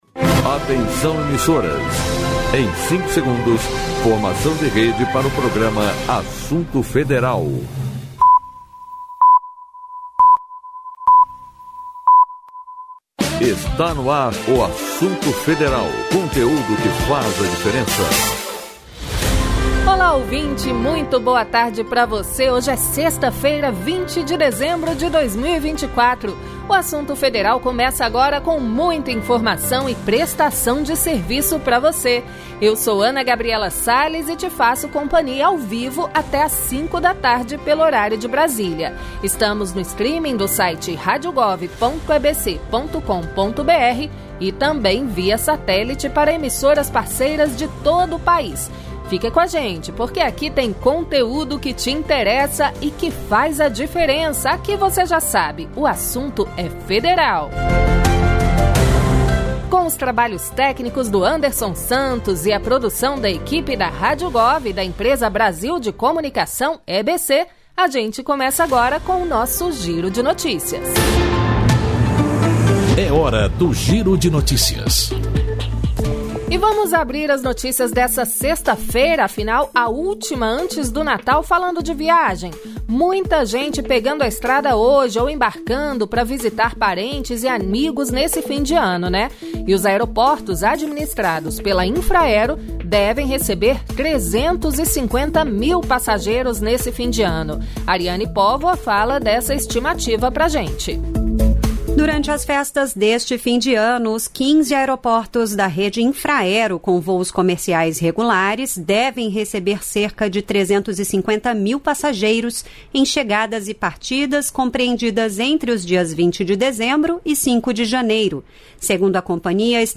O programa vai ao ar, ao vivo, de segunda a sexta, das 16h às 17h pelo streaming do site Rádio Gov e por satélite, disponível para emissoras de todo o país.